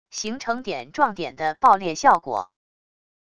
形成点撞点的爆裂效果wav音频